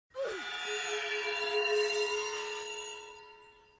Wipe Sound
roblox-rogue-lineage-wipe-sound-effect.mp3